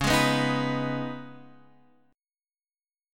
C#7sus2 chord